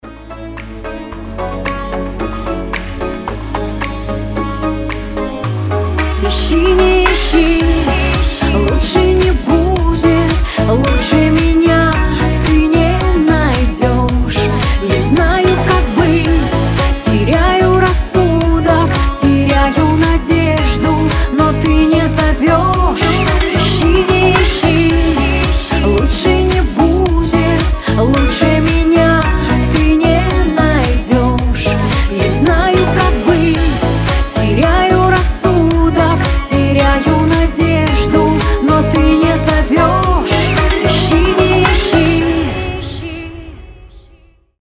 • Качество: 320, Stereo
поп